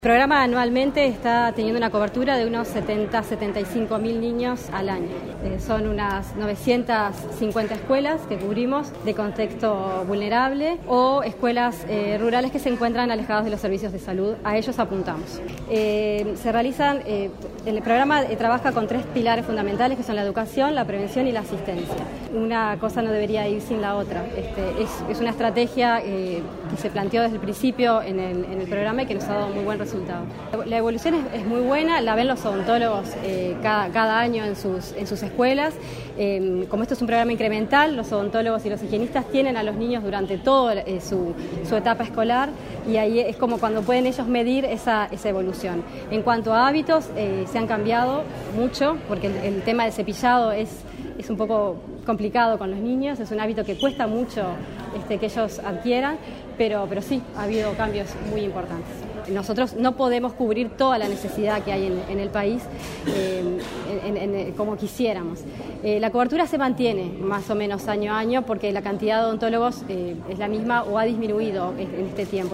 El Programa de Salud Bucal Escolar, Presidencia de la República, Facultad de Odontología de la UdelaR y la Intendencia de Montevideo, realizaron un acto de celebración de la 13ª Semana de la Salud Bucal.